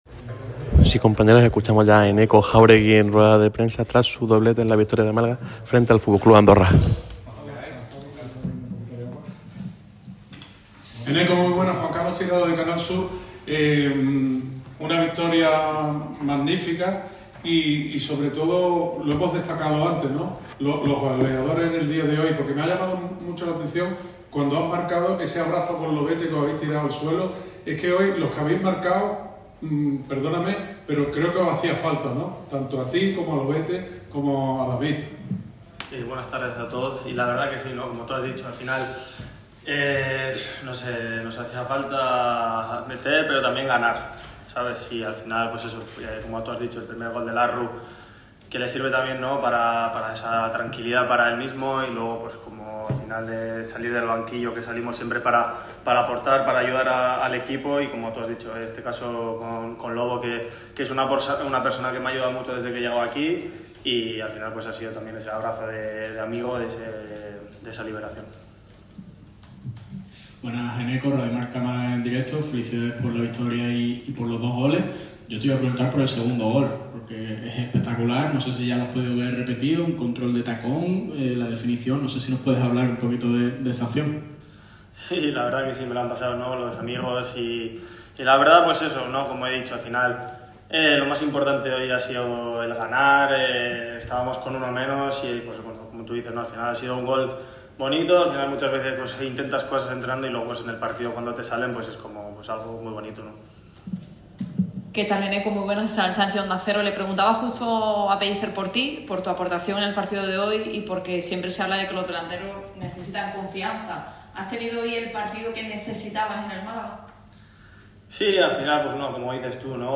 en zona mixta tras sus dos goles en la victoria por 4-1